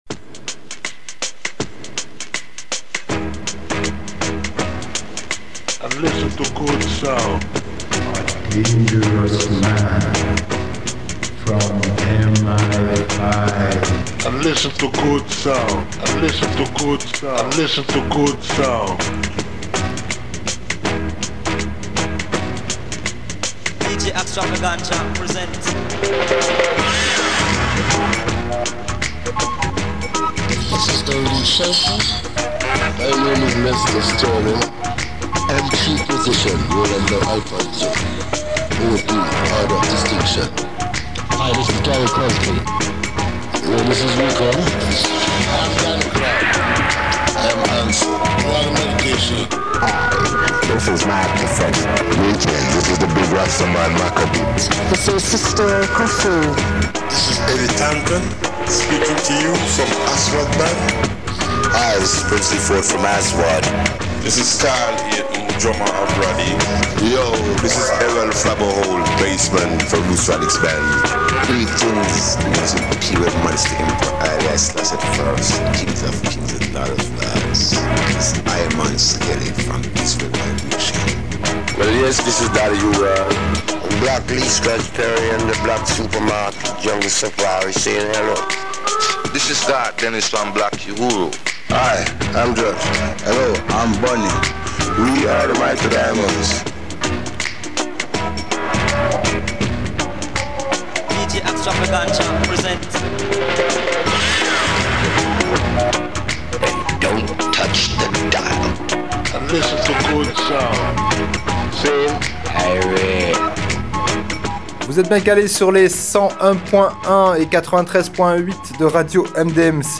radio show !